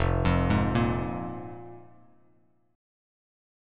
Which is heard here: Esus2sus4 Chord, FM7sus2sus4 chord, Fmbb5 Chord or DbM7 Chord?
Fmbb5 Chord